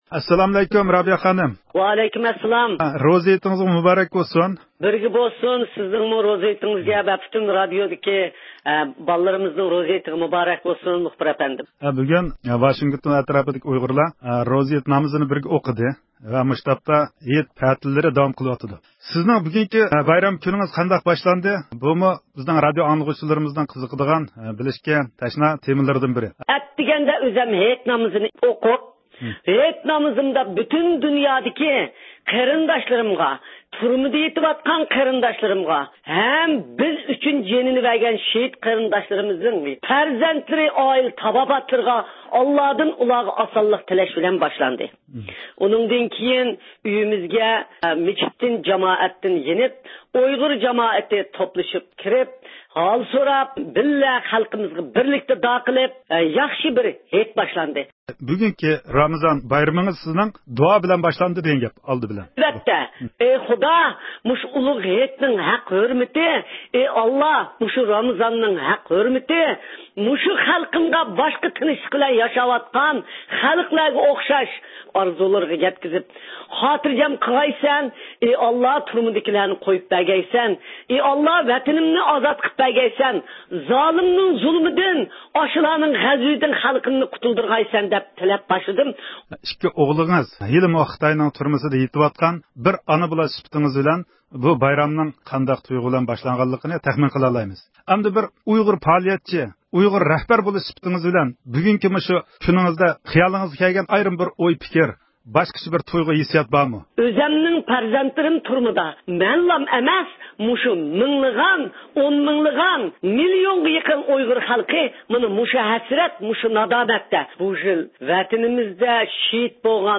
ئۇيغۇر مىللىي ھەرىكىتى رەھبىرى رابىيە قادىر خانىم، بۈگۈن روزا ھېيت مۇناسىۋىتى بىلەن رادىئومىز زىيارىتىنى قوبۇل قىلىپ، ۋەتەن ئىچى-سىرتىدىكى ئۇيغۇرلارغا ئۆزىنىڭ ھېيتلىق ئارزۇ ۋە تىلەكلىرىنى بايان قىلدى.